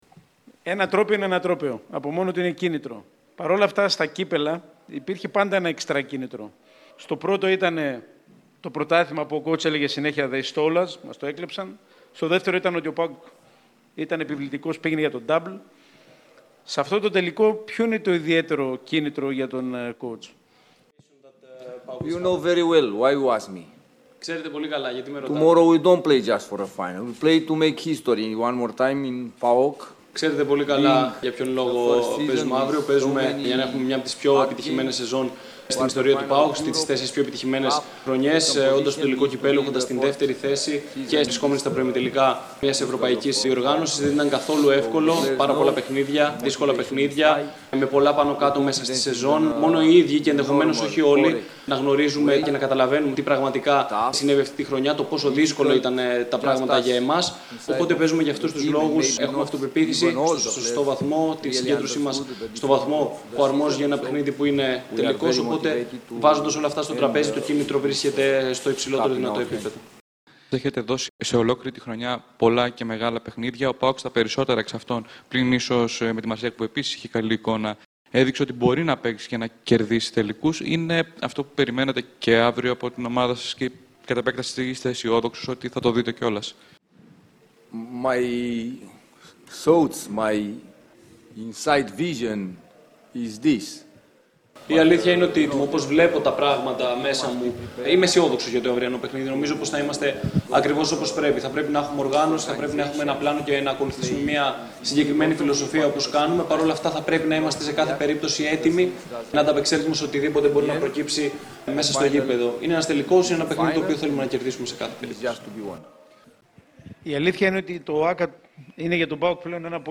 Το ότι ο ΠΑΟΚ είναι έτοιμος για ένα ιστορικό επίτευγμα τόνισε ξανά ο Ρουμάνος προπονητής στη συνέντευξη Τύπου ενόψει του τελικού του Κυπέλλου Ελλάδας (21/5, 20:00, ΕΡΑΣΠΟΡ).
Ακούστε τις δηλώσεις του Ραζβάν Λουτσέσκου: